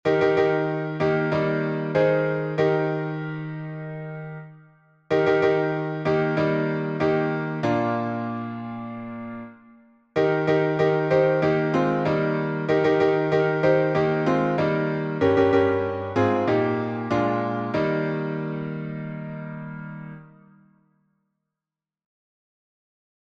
Words and music by Unknown artist Key signature: E flat major (3 flats) Meter: Irregular Public